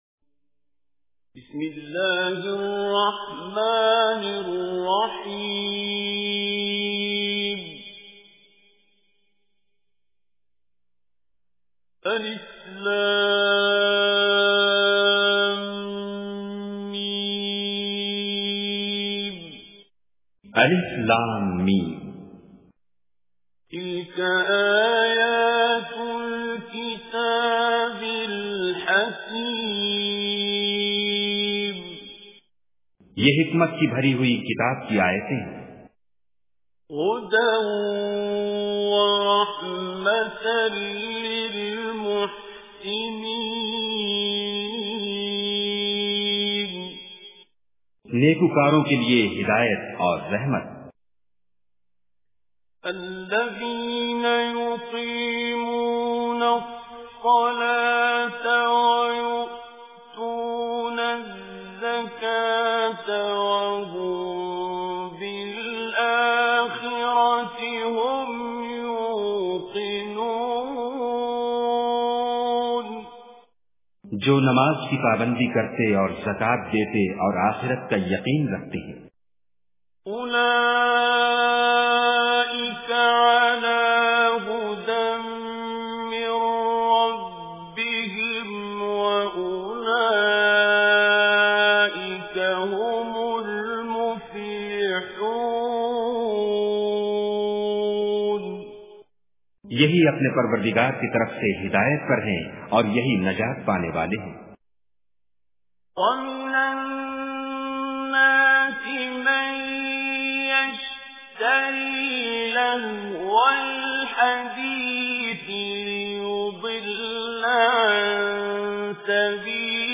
Surah Luqman Recitation with urdu Translation
surah-Luqman.mp3